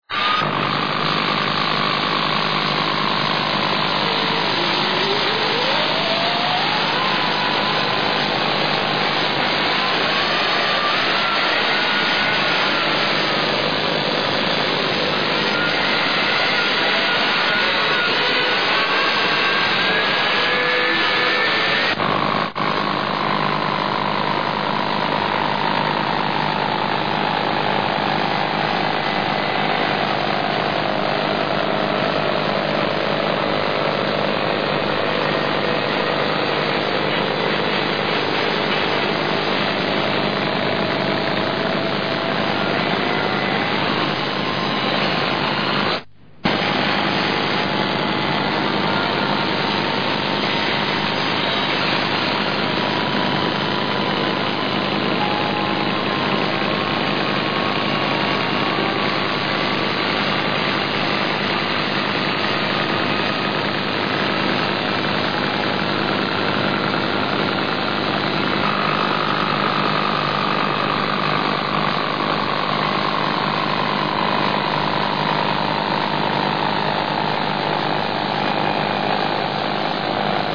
Listen to the jamming noise MP3 file (178KB)
VOP beams a radio programme to Zimbabwe every evening from 7 to 8 p.m. (18:00 to 19:00 GMT) on the 7.120KHz shortwave frequency using a relay station belonging to the Dutch public radio station Radio Netherlands on the island of Madagascar, in the Indian Ocean.
A frequently-used jamming technique is to broadcast a noise on the same frequency as the target signal using another radio station's transmitters.
vop_jamming_noise.mp3